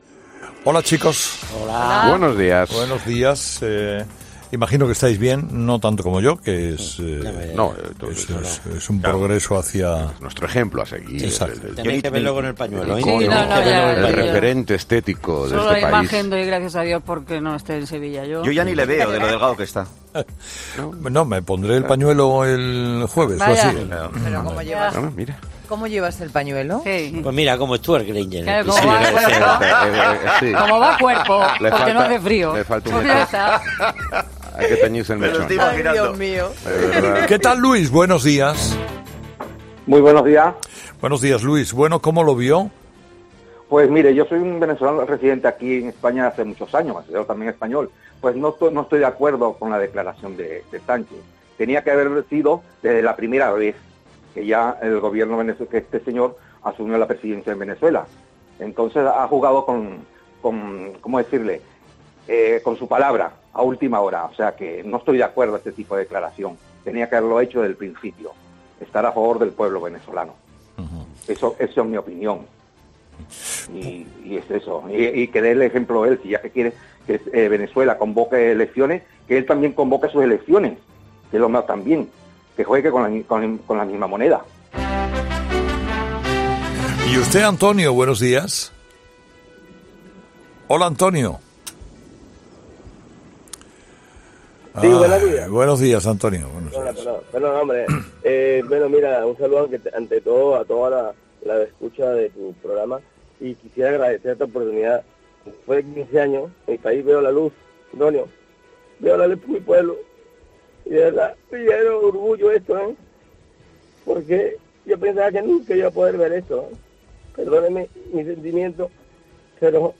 Los oyentes de Herrera han reprobado al presidente del Gobierno por pretender que Guaidó convoque elecciones mientras él se aferra al poder